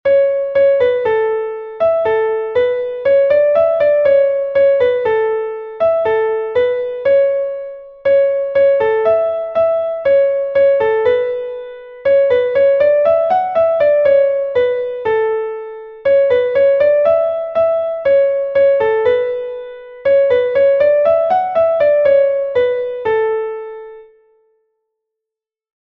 Laridé